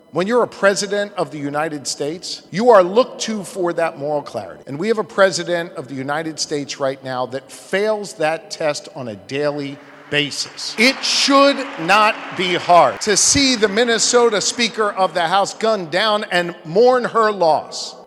Shapiro Speaks On Political Violence
Pennsylvania Governor Josh Shapiro was at the National Cathedral in Washington this week participating in a conversation about political violence.